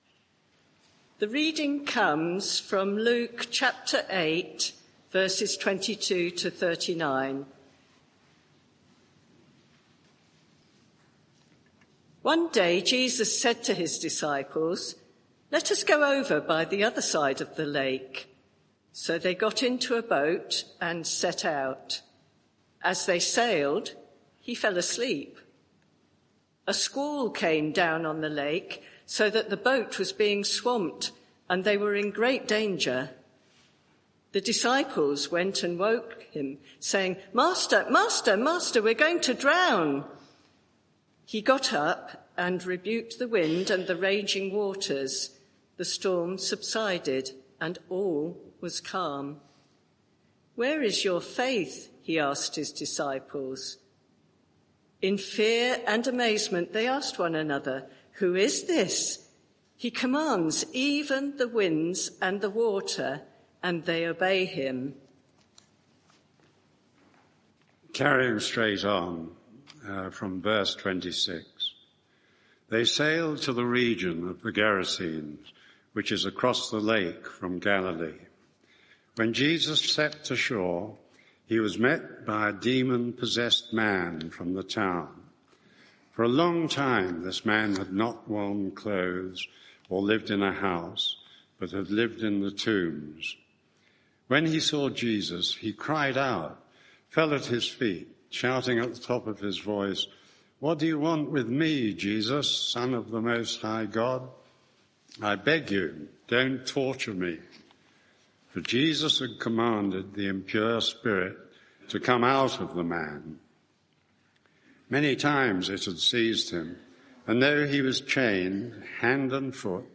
Media for 11am Service on Sun 06th Apr 2025 11:00 Speaker
Passage: Luke 8:22-39 Series: What a Saviour! Theme: Luke 8:22-39 Sermon (audio) Search the media library There are recordings here going back several years.